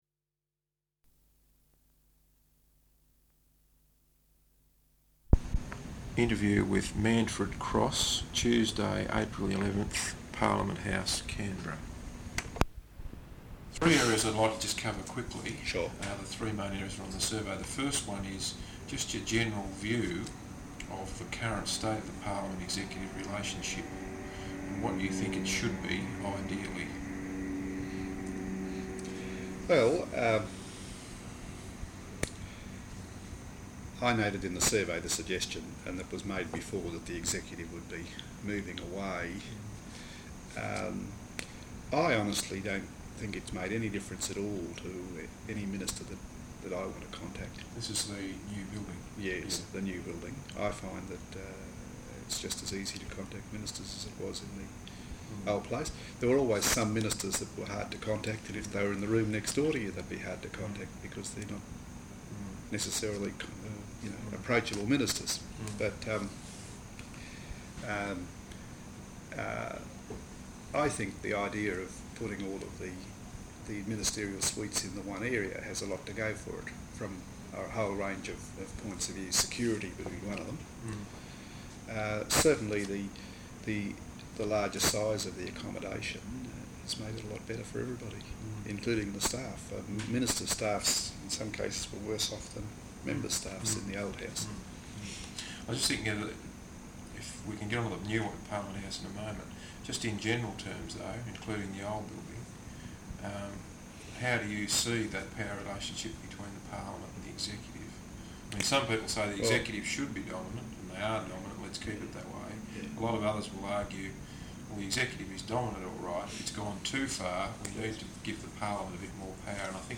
Interview with Manfred Cross, Parliament House, Canberra April 11th, 1989.